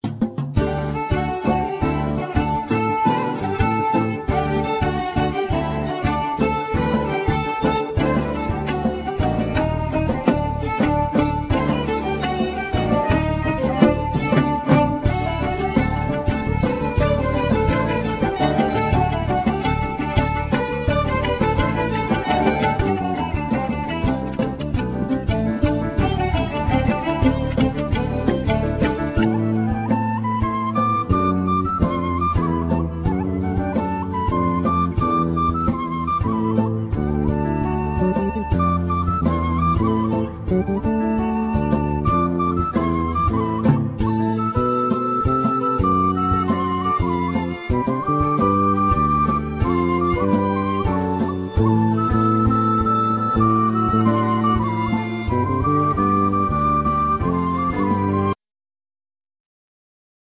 Vocals
Tabla
Piano,Keyboards
Sitar
Violin,Viola,Cello,Contra bass